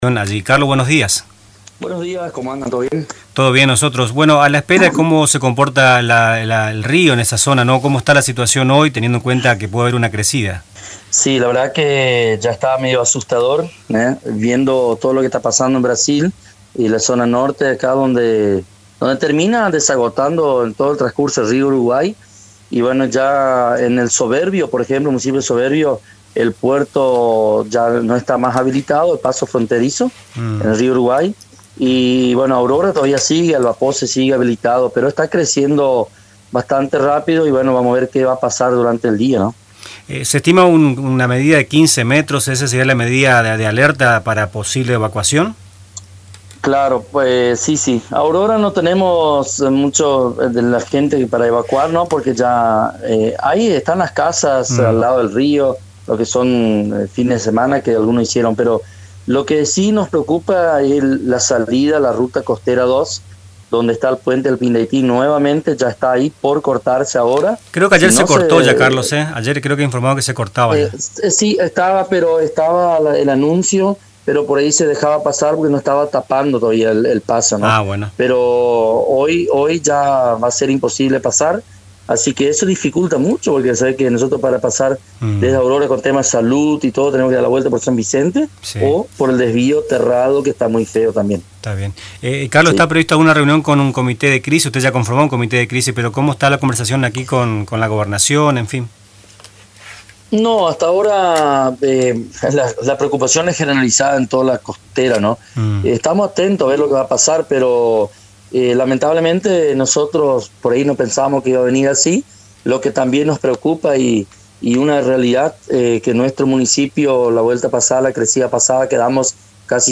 En una entrevista exclusiva con Radio Tupa Mbae, el Intendente de Aurora, Carlos Goring, expresó su preocupación ante la inminente crecida del río Uruguay.
M-CARLOS-GORING.mp3